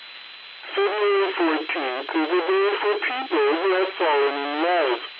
07_giant_radio.wav